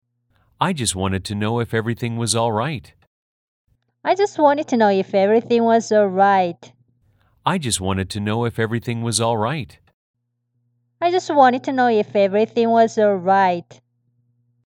다음 문장을 빠른 속도로 녹음한 원어민 속도대로 따라 해보세요.
*어이쿠~ 어제 열심히 녹음했는데 뭐가 문제인지 이 녹음에 잡음이... ㅠㅠ